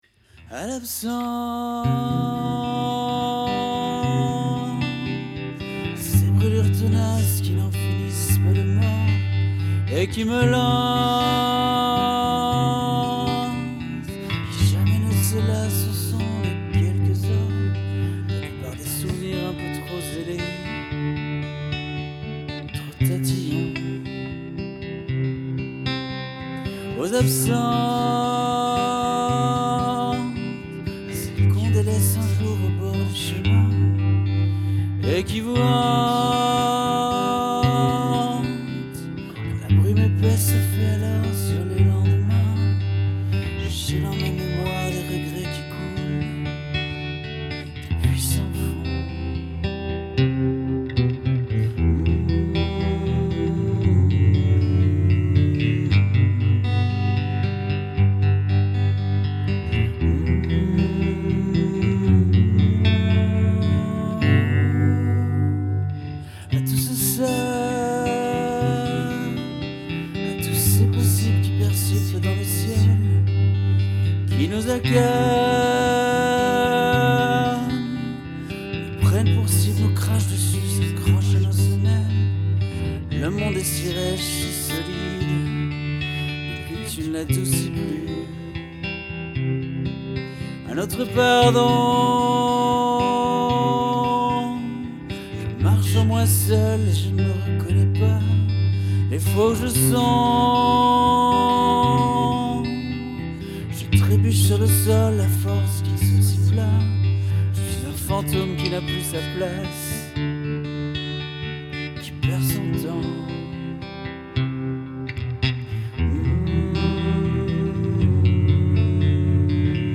Version “cosmique”, bricolée le 25 février 2015.
• Voix (le serviteur souffrant)
• 2ème voix (le fantôme)
• Guitare